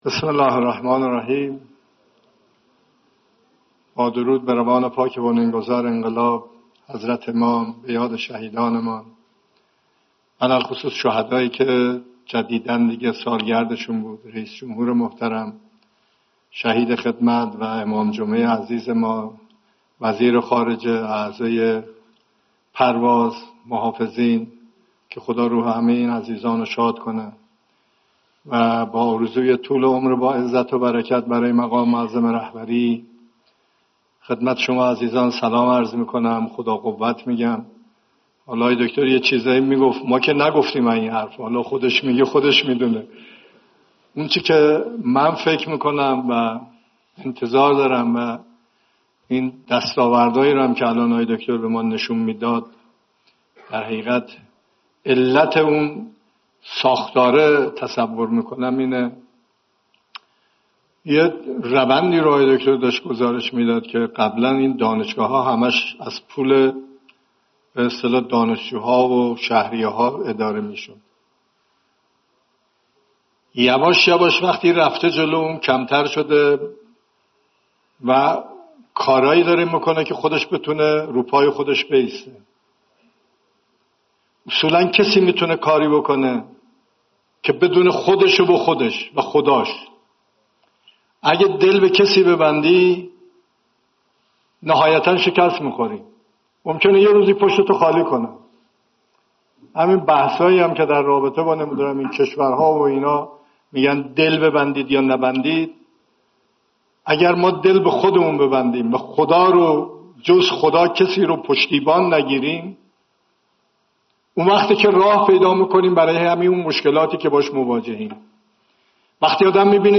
سخنان رئیس جمهور در سالروز تاسیس دانشگاه آزاد اسلامی